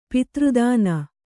♪ pitř dāna